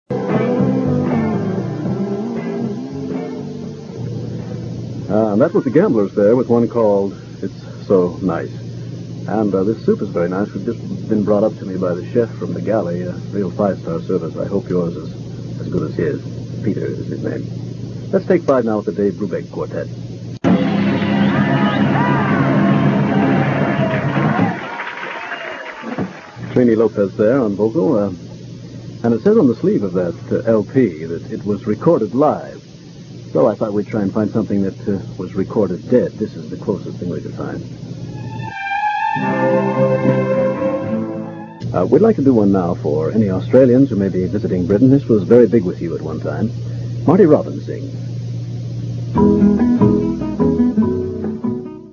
Sunday morning show